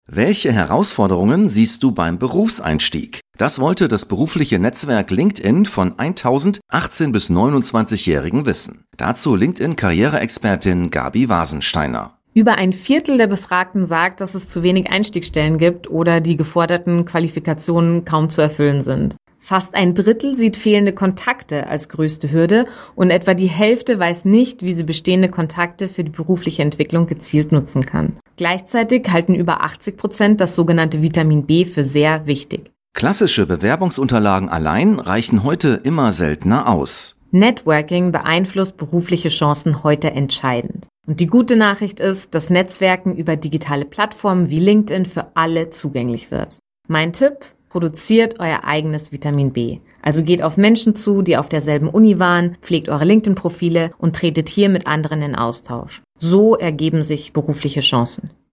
rts-beitrag-berufseinstieg.mp3